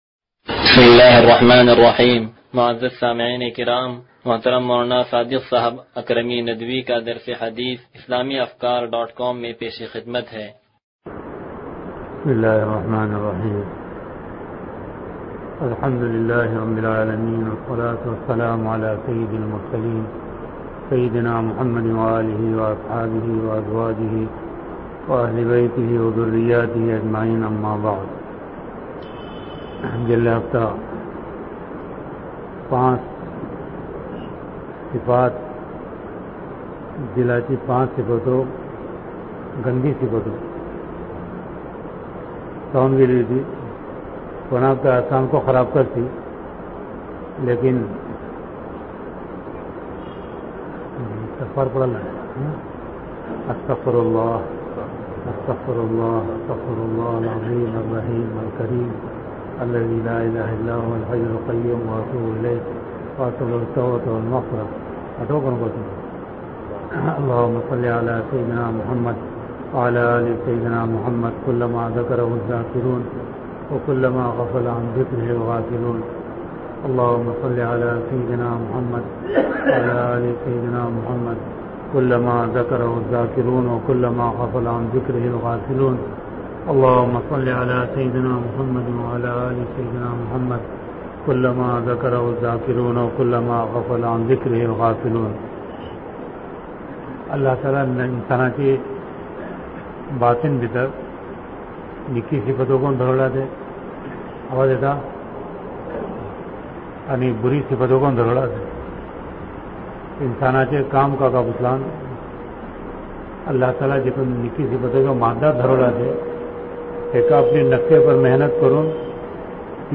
درس حدیث نمبر 0214